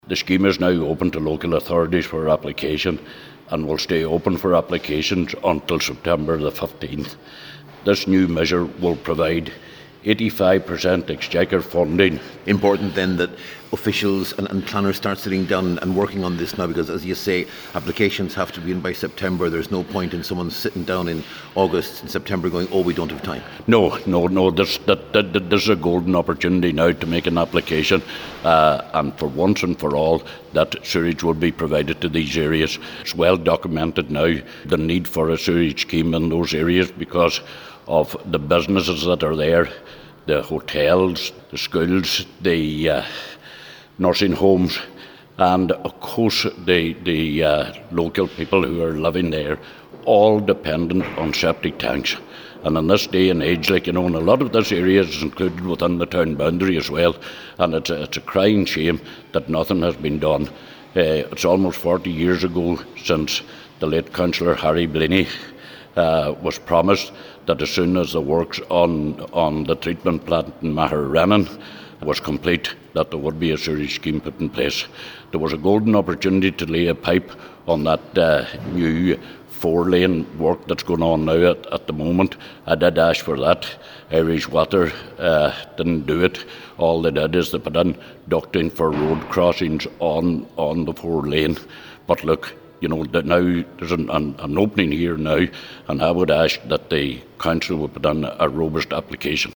He told a Municipal District meeting that this work is necessary, and any available funding should be seized: